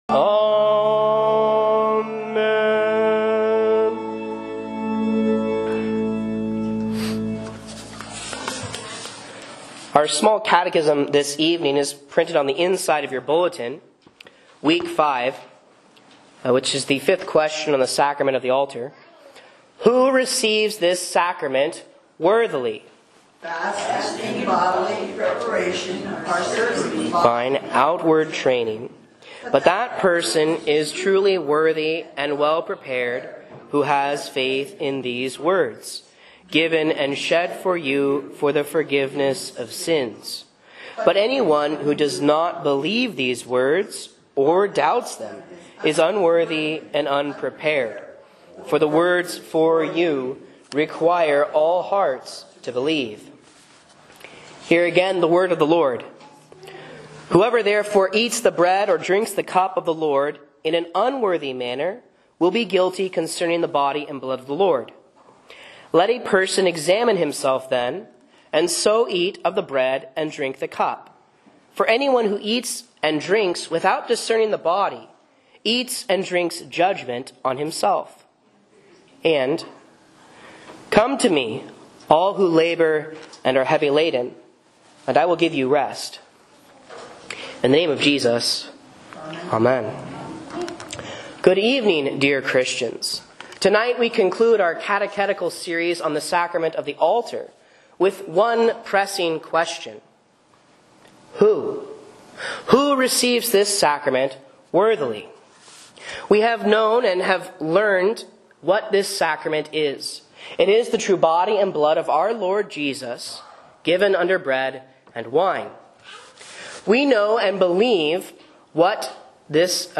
Sermons and Lessons from Faith Lutheran Church, Rogue River, OR
A Sermon on 1 Cor. 11, Matt. 11.28, and SC VI.5 for Lent 5 Midweek